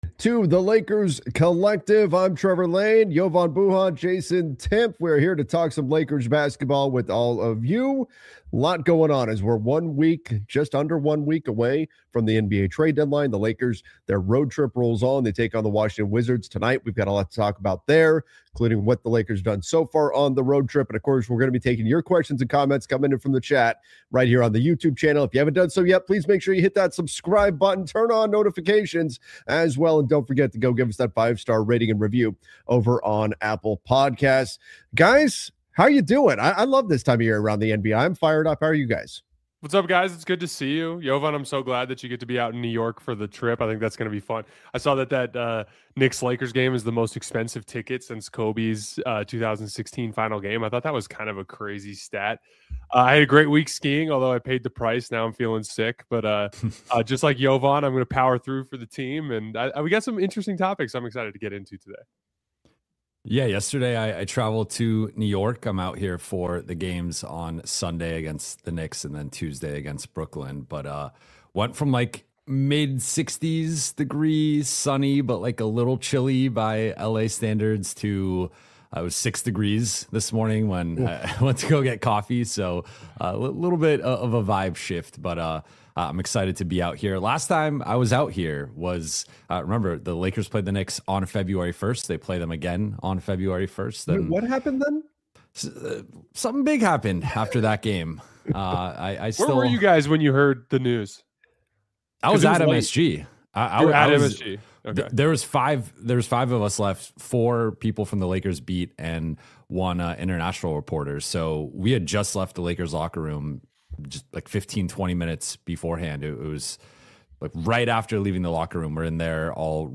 a weekly Los Angeles Lakers roundtable